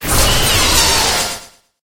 Cri de Duralugon dans Pokémon HOME.